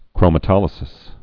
(krōmə-tŏlĭ-sĭs)